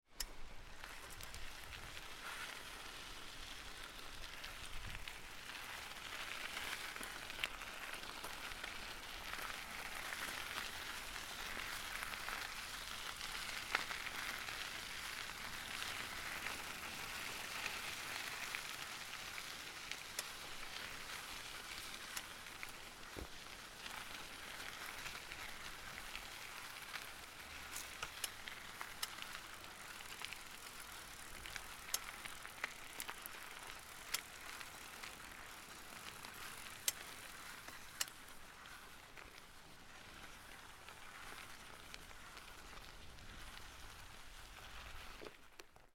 Шуршание колес велосипеда по лесным грунтовым дорогам